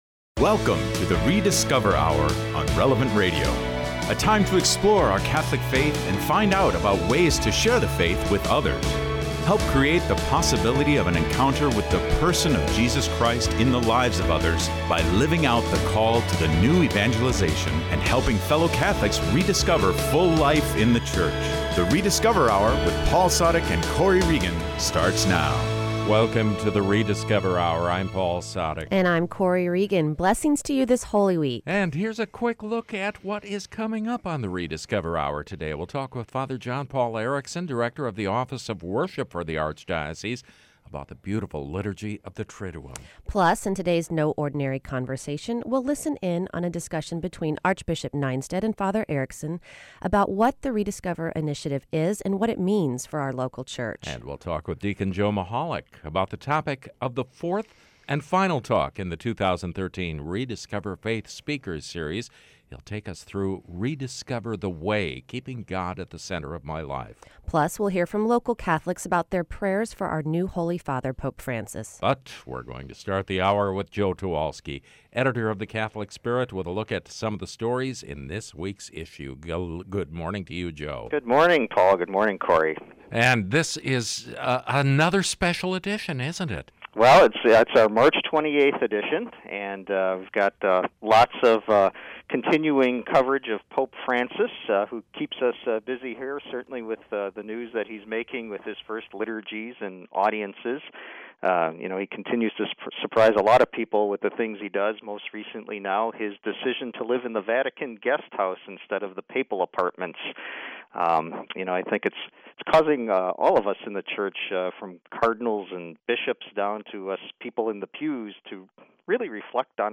Reflexione sobre la belleza del Triduo Pascual y participe en el debate sobre el profundo significado de la cumbre del año litúrgico de la Iglesia. Además, escuche a los católicos locales hablar sobre sus oraciones por el papa Francisco.